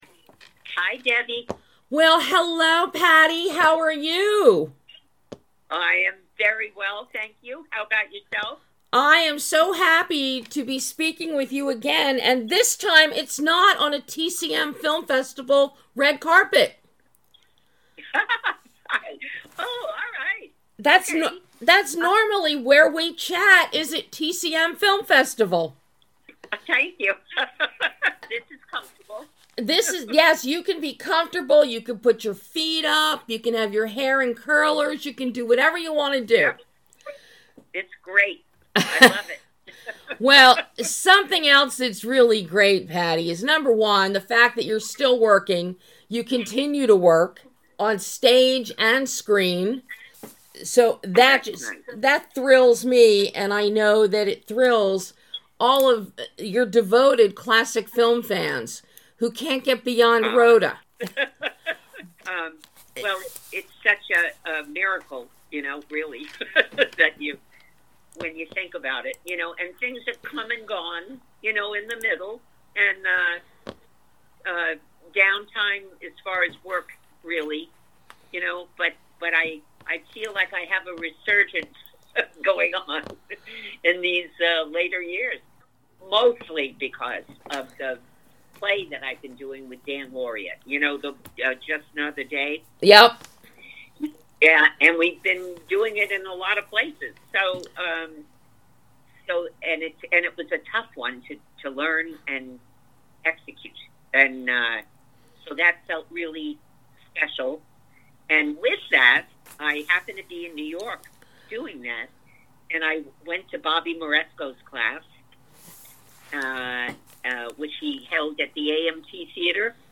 An in-depth conversation with classic film star PATTY McCORMACK as she talks about her new film STOP TIME, and so much more.